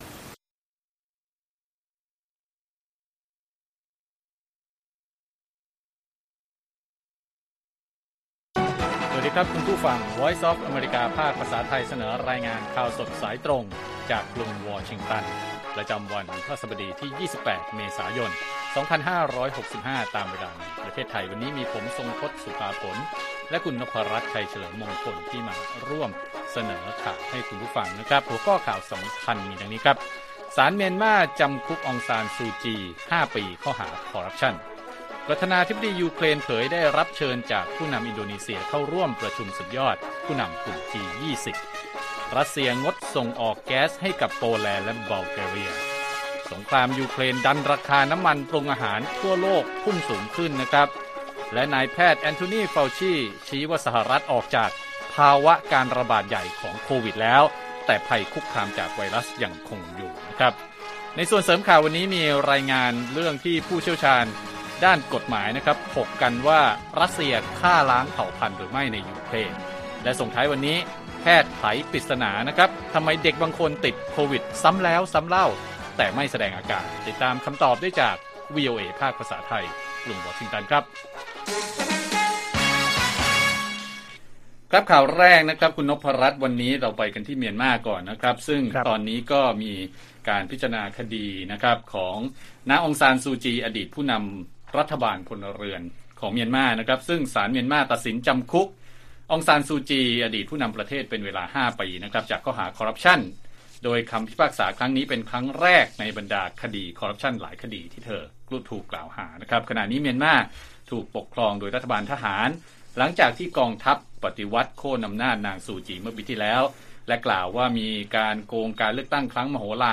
ข่าวสดสายตรงจากวีโอเอไทย 28 เม.ย. 2565